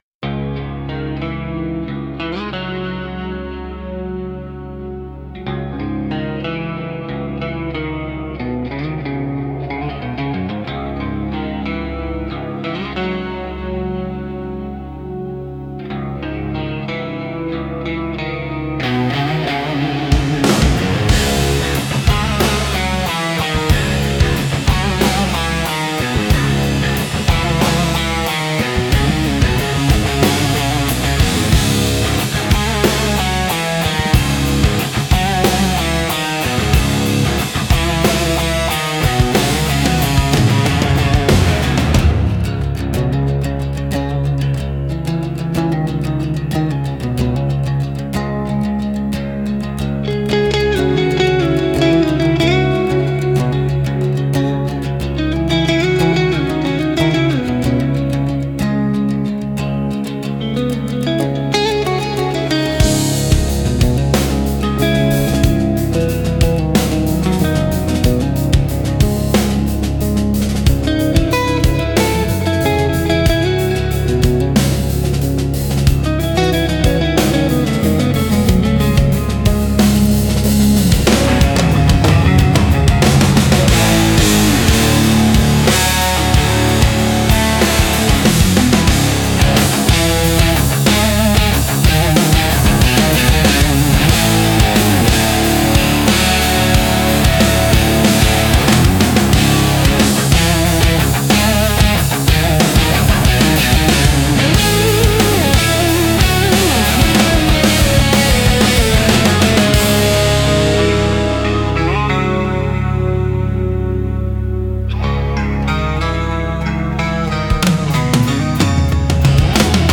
Instrumental - Mojave Tremolo Prayer